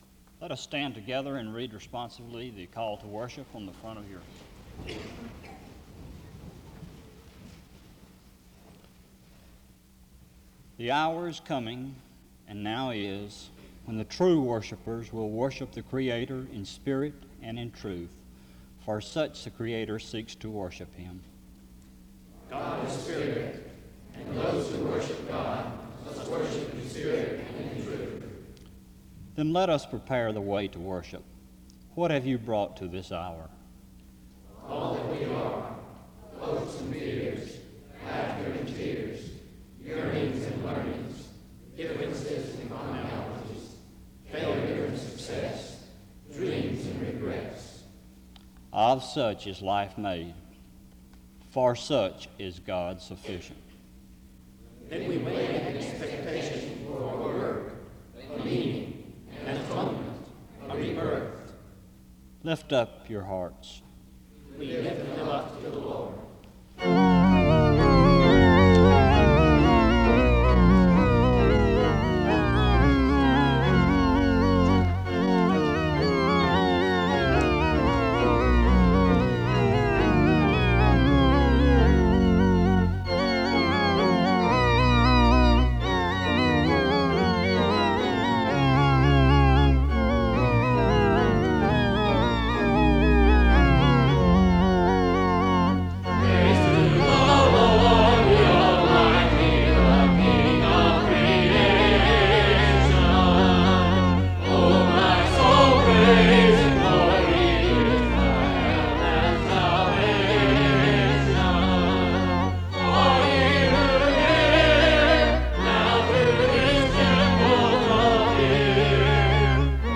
The concert opens with a responsive reading from 0:00-1:06. Music plays from 1:07-4:03. A prayer of confession is given from 4:12-5:24. Special music plays from 5:52-9:43. A prayer is offered from 10:00-11:43. 2 Corinthians 5:17-19 and Luke 4:16-21 is read from 12:02-13:50. Music plays from 14:02-19:19.